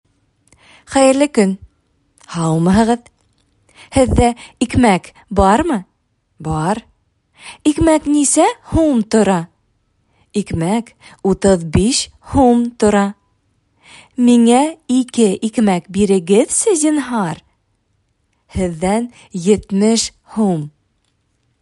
Предлагаем вам прослушать и прочитать диалог между продавцом и покупателем.
Диалог 1